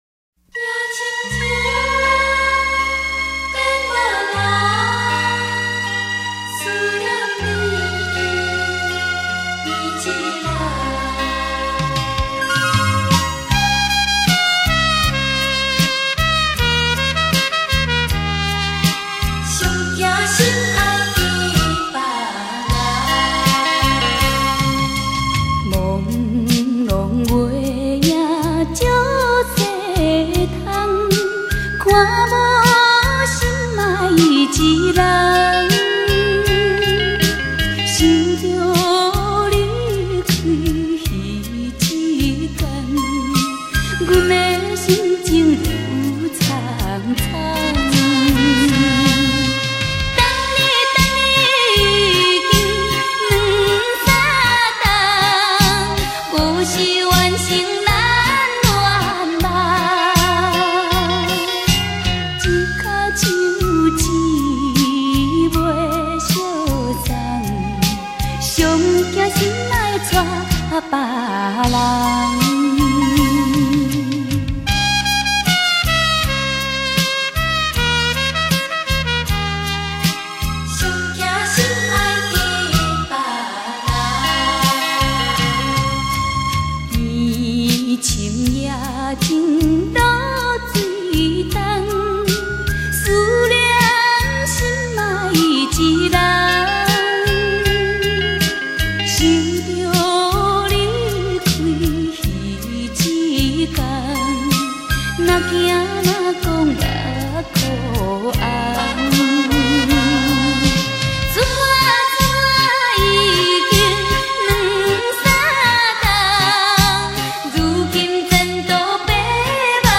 出如此百转千回的歌，她的歌声清甜，声音带